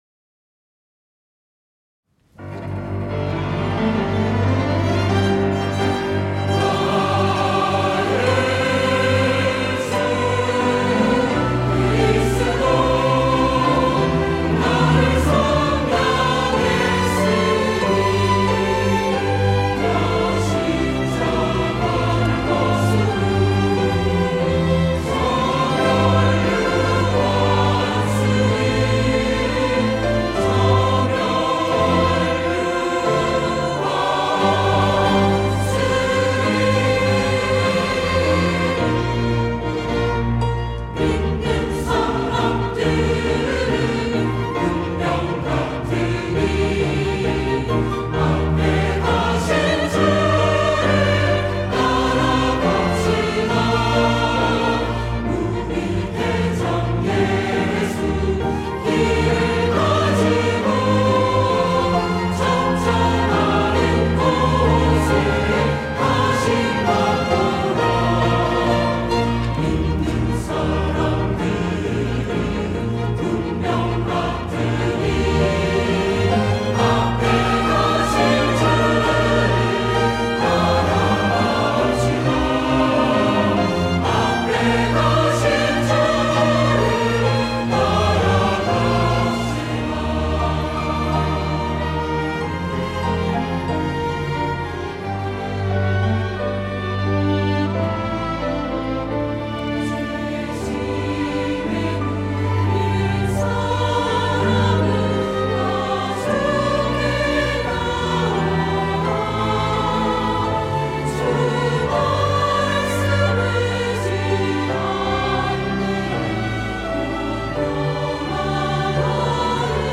호산나(주일3부) - 믿는 사람들은 군병 같으니
찬양대